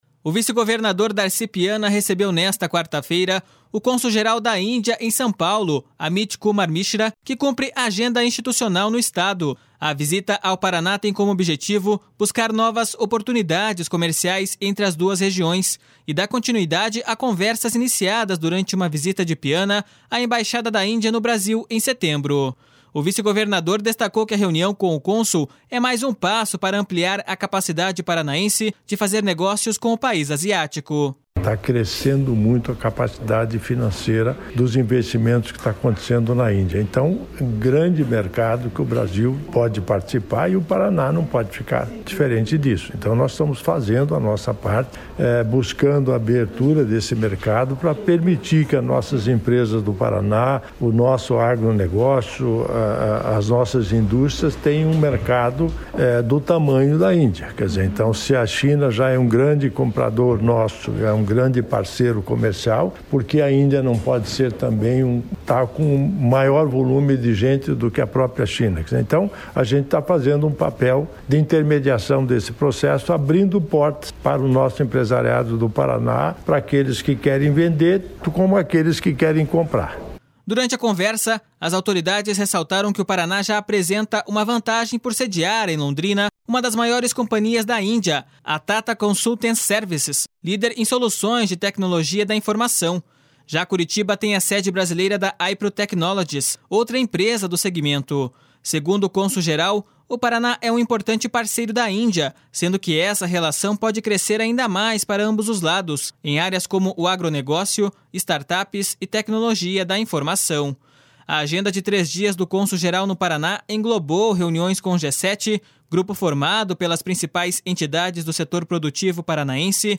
O vice-governador destacou que a reunião com o cônsul é mais um passo para ampliar a capacidade paranaense de fazer negócios com o país asiático.// SONORA DARCI PIANA.//